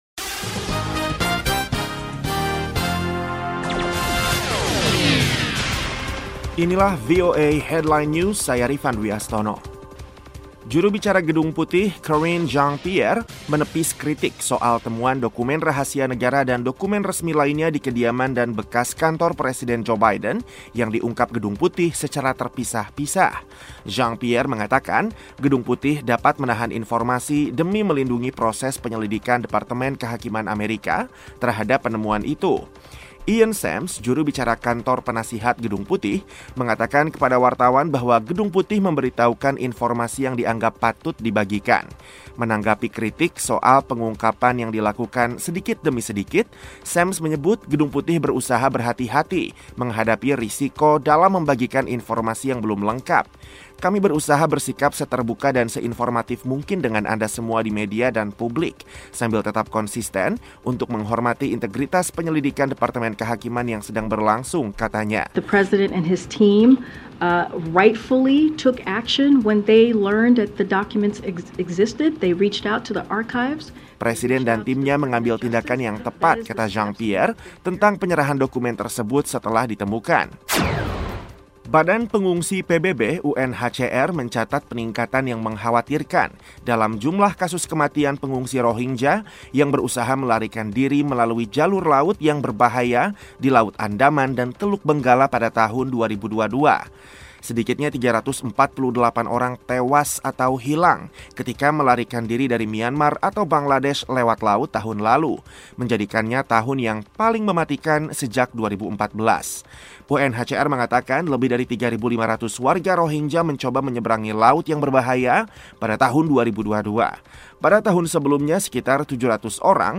VOA Headline News